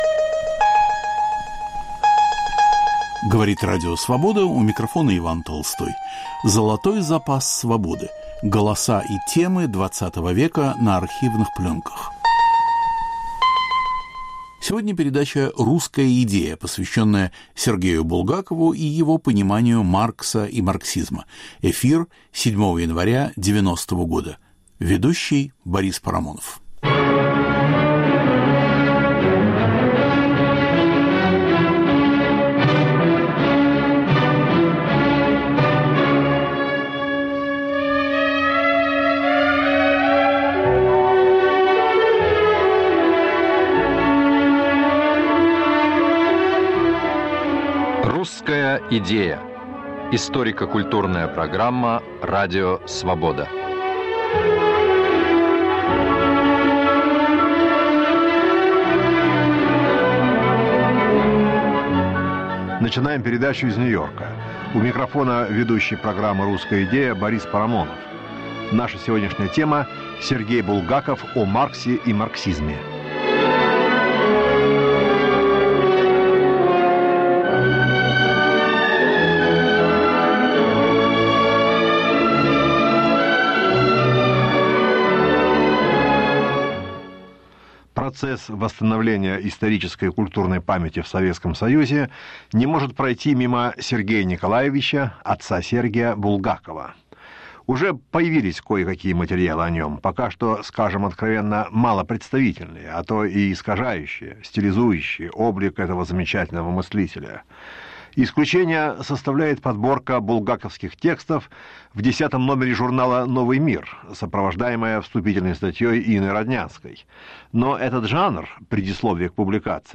Золотой запас Свободы. Голоса и темы XX века на архивных пленках. Русская идея: Сергей Булгаков.
Автор и ведущий Борис Парамонов об отношении Сергея Булгакова к Марксу и марксизму.